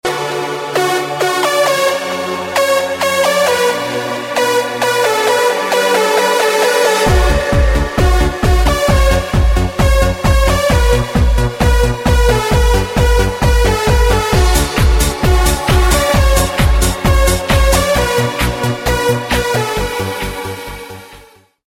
Categoria Elettronica